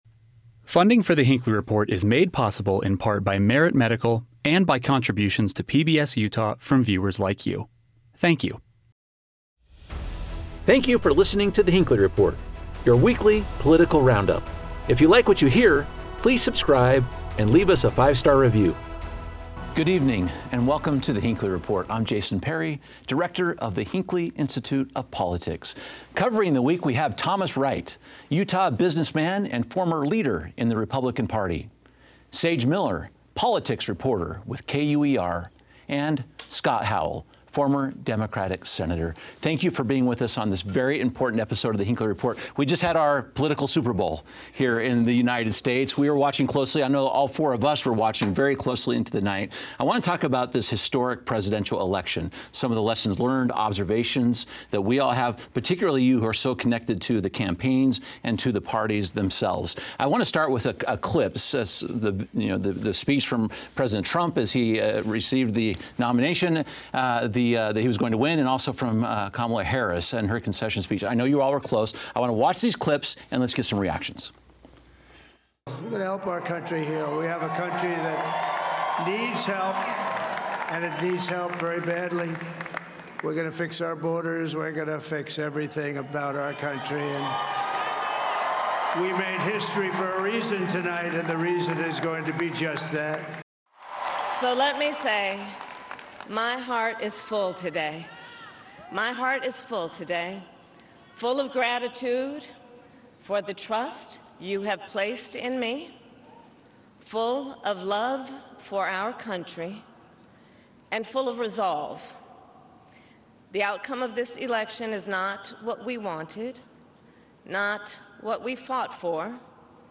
Our expert panel evaluates what messaging resonated with the electorate, which demographics played a decisive role, and what the results tell us about the priorities of Utahns.